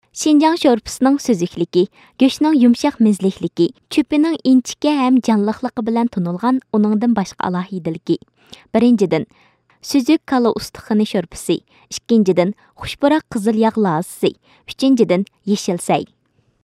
商业广告